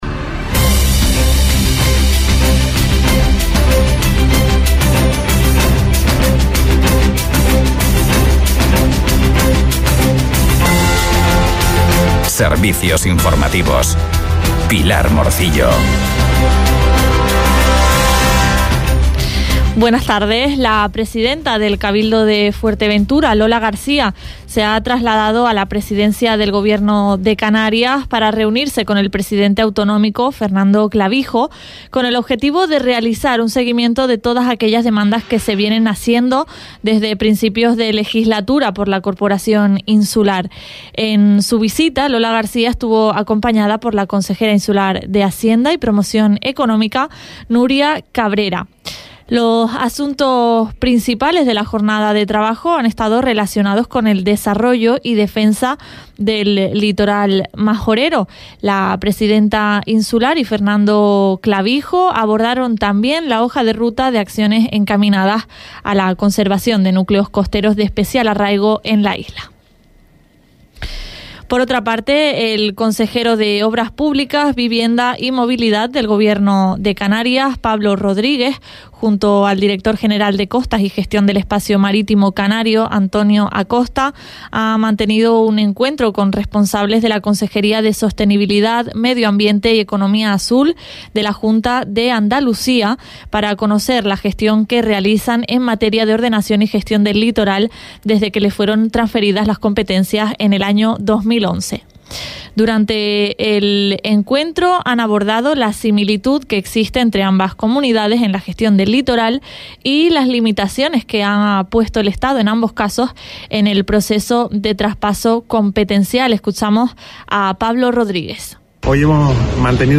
Informativos en Radio Sintonía – 21.12.23